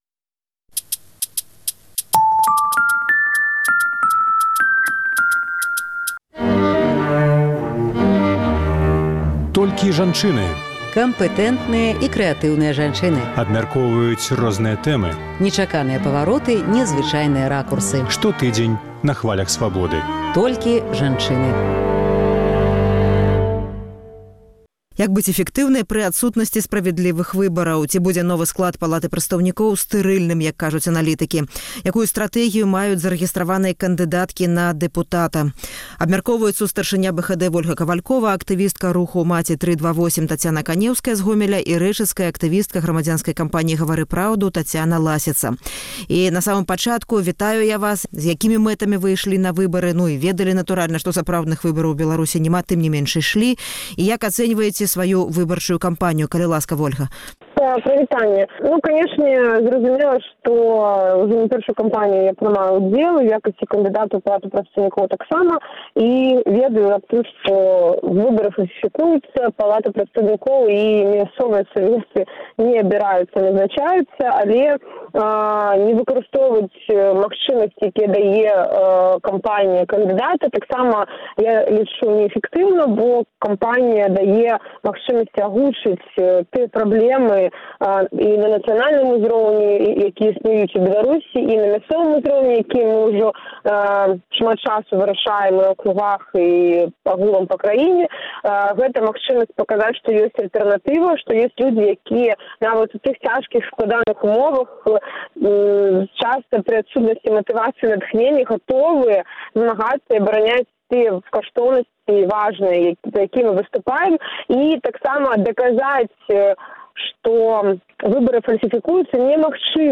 Ці будзе новы склад Палаты прадстаўнікоў стэрыльным? Якія магчымасьці маюць прызначаныя дэпутаты ў парлямэнце? Абмяркоўваюць тры зарэгістраваныя кандыдаткі ў дэпутаты.